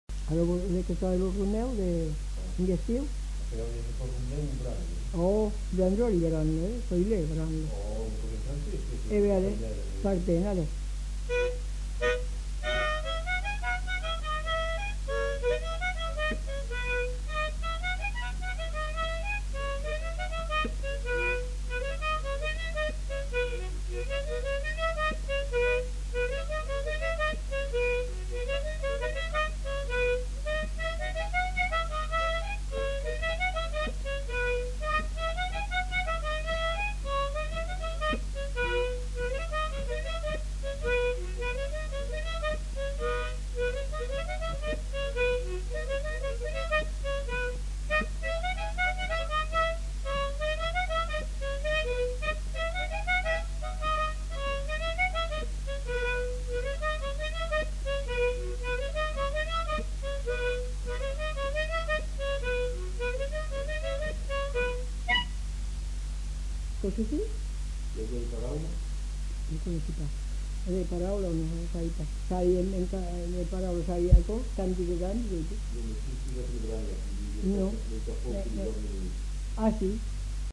Aire culturelle : Haut-Agenais
Genre : morceau instrumental
Instrument de musique : harmonica
Danse : rondeau
Notes consultables : Discussion sur le rondeau en fin de séquence.